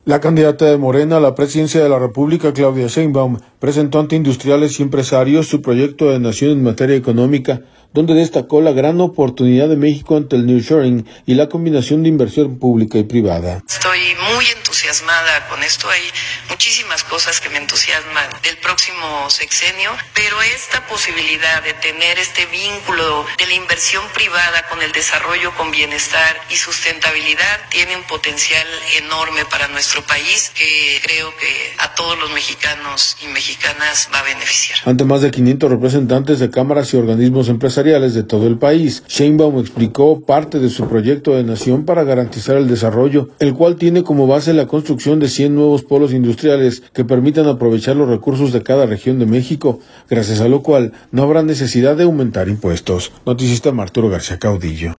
La candidata de Morena a la Presidencia de la República, Claudia Sheinbaum, presentó ante industriales y empresarios, su proyecto de Nación en materia económica, donde destacó la gran oportunidad de México ante el nearshoring y la combinación de inversión pública y privada.